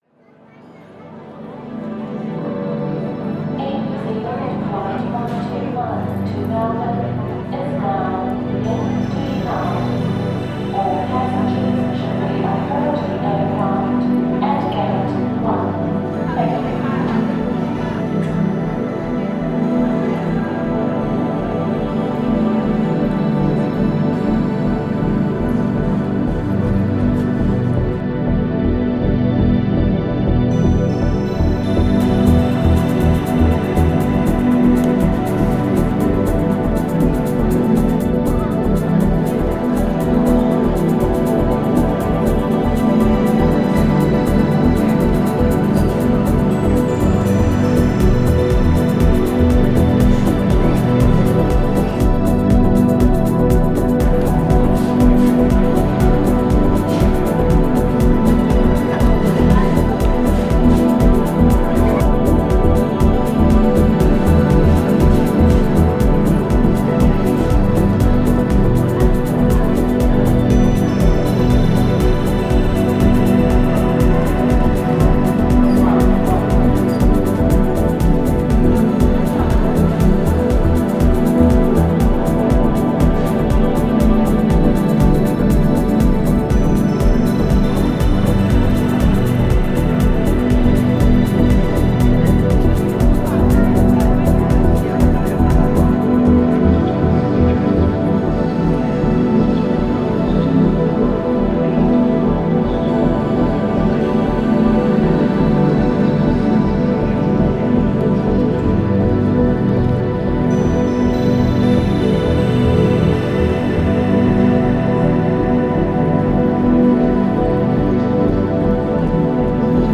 Genre: Ambient/Deep Techno/Dub Techno.